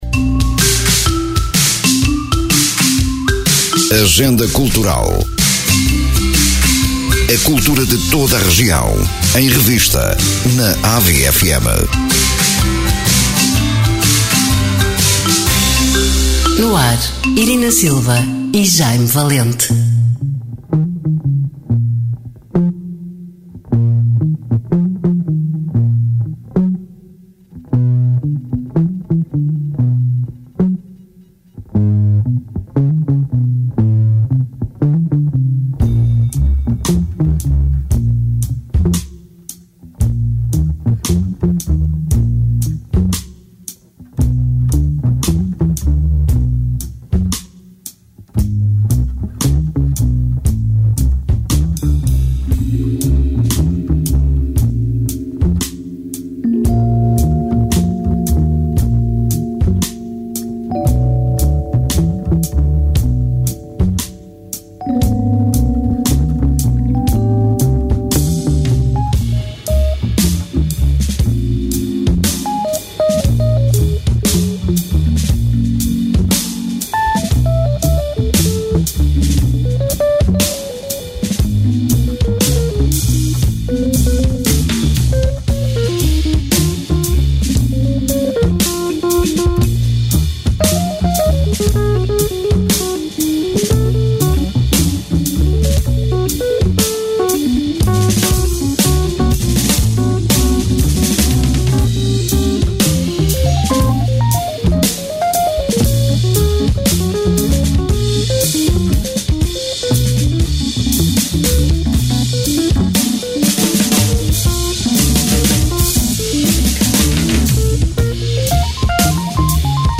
Emissão: 16 de Abril 2024 Descrição: Programa que apresenta uma visão da agenda cultural de Ovar e dos Concelhos vizinhos: Estarreja, Feira, Espinho, Oliveira de Azeméis, São João da Madeira, Albergaria-a-Velha, Aveiro e Ílhavo. Programa com conteúdos preparados para ilustrar os eventos a divulgar, com bandas sonoras devidamente enquadradas.